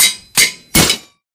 anvil_land.ogg